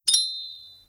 snd_ui_gold.wav